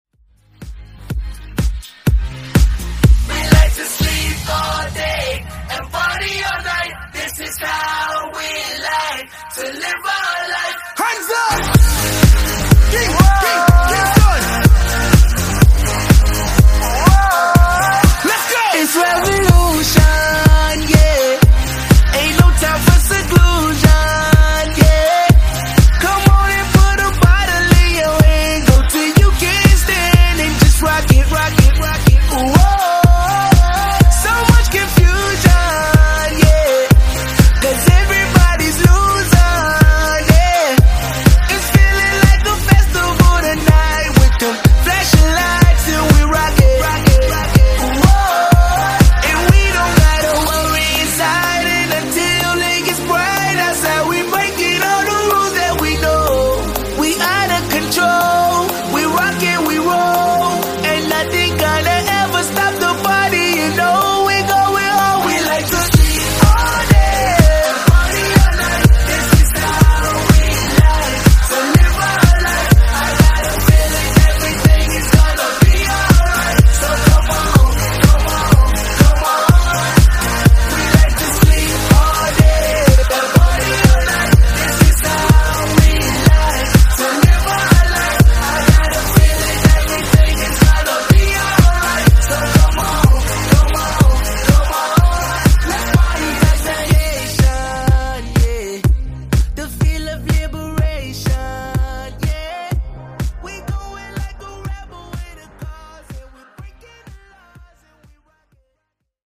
Genres: DANCE , RE-DRUM
Clean BPM: 128 Time